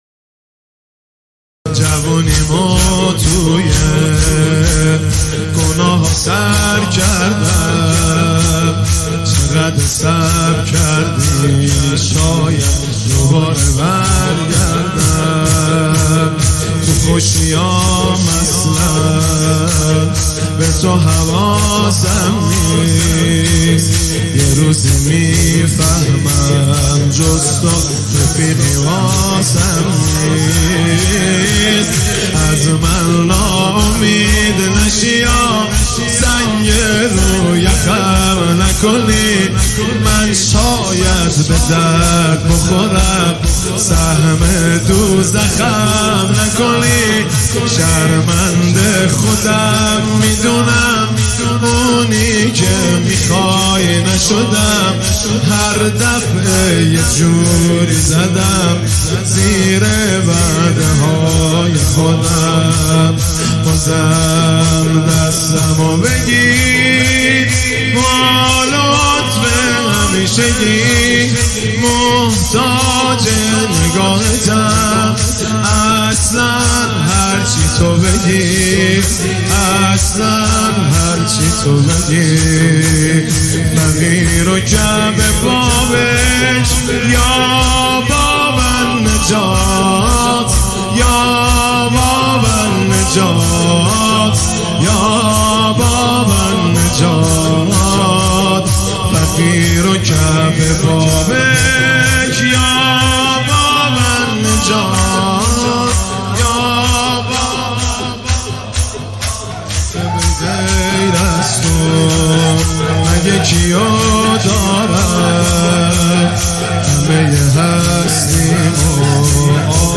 نواهنگ